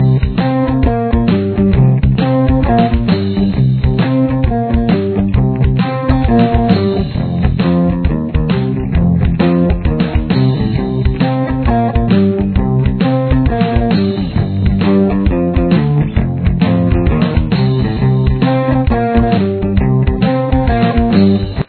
Your basic 12-bar blues progression starts here.
Guitar 1 (rhythm)